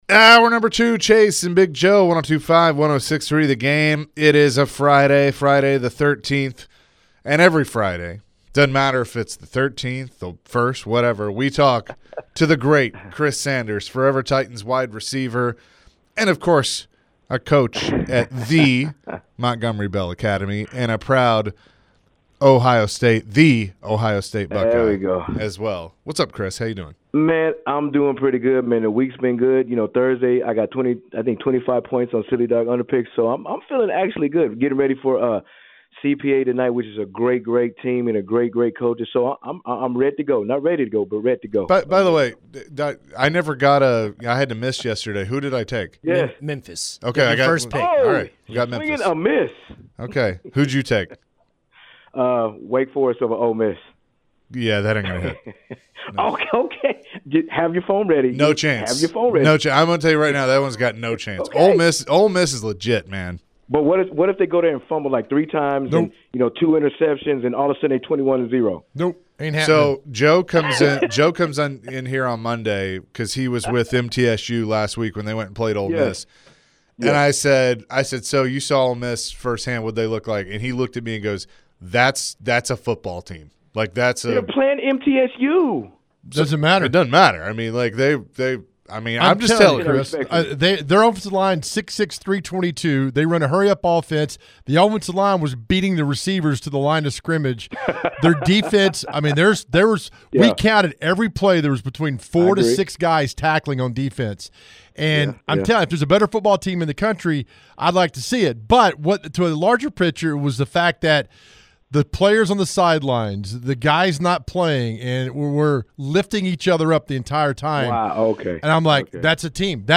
The guys chatted with forever Titan Chris Sanders. Chris previewed this weekend's matchup between the Titans and Jets. What are some concerns that Chris has for the Titans this weekend?